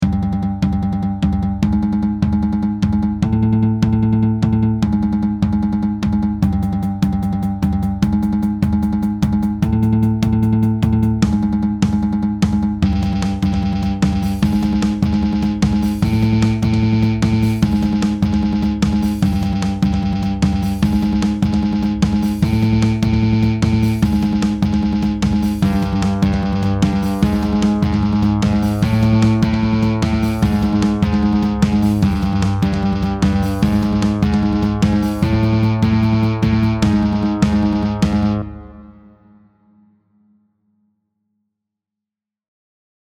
Sneaky music…
sneaky.mp3